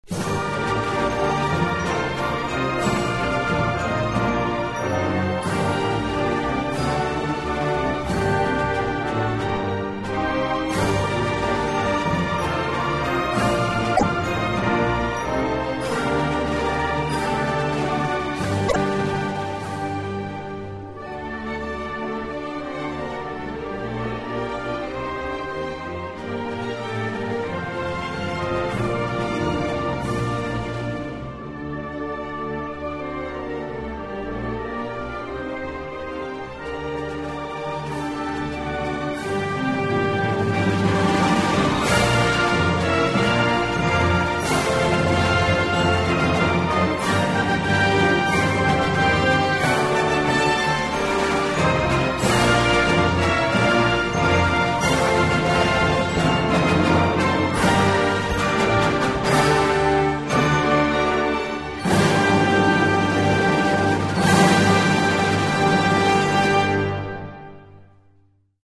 Instrumentalversion 1 (472 k .mp3)